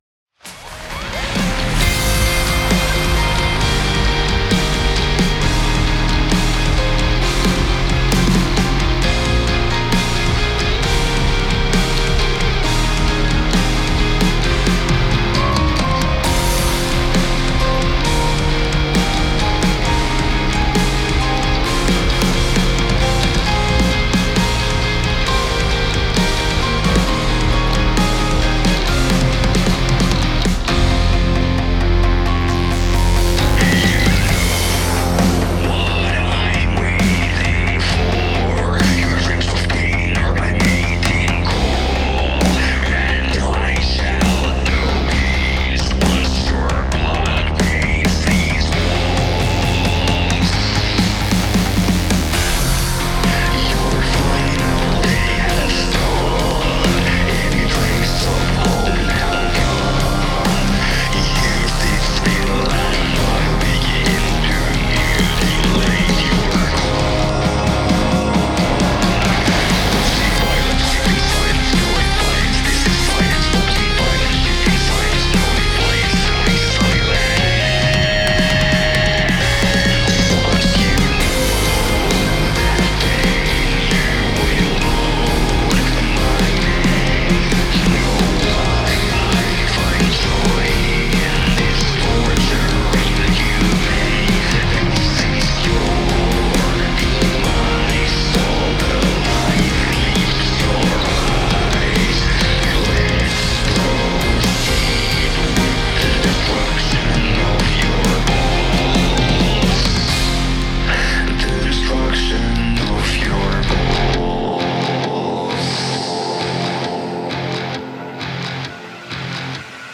The master sucks, we know, but it’s the thought that counts.